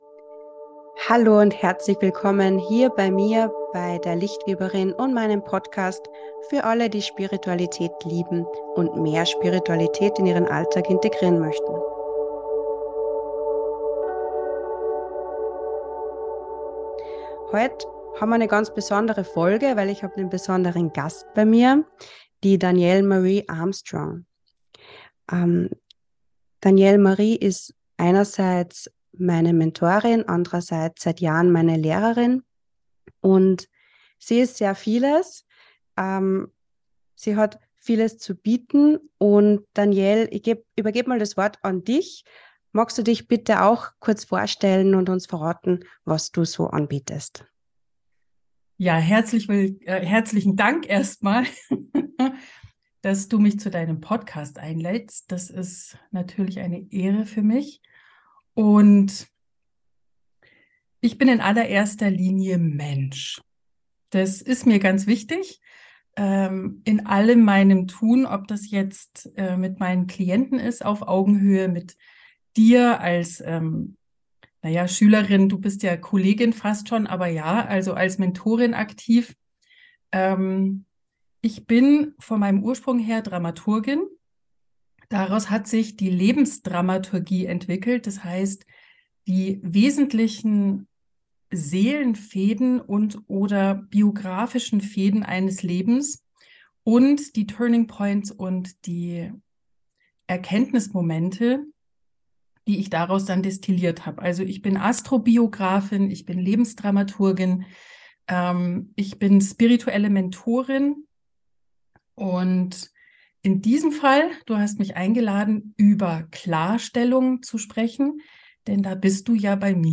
Viel Spass mit diesem super informativen Interview.